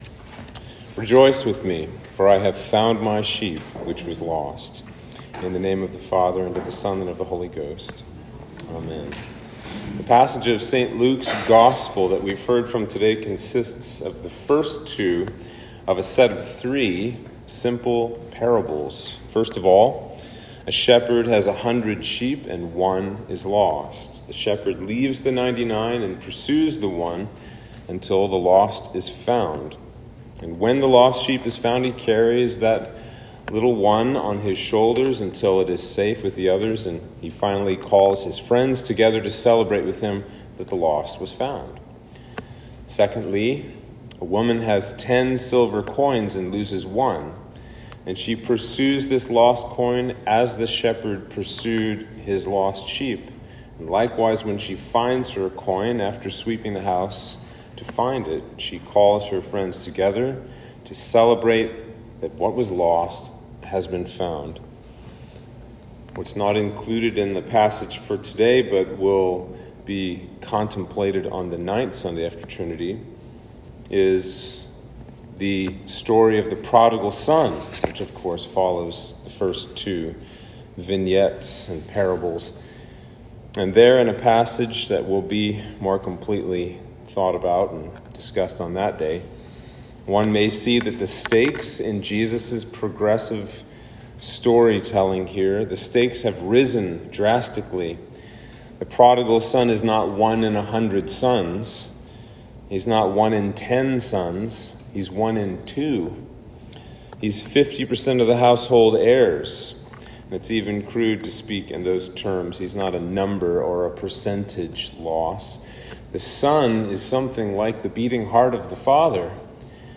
Sermon for Trinity 3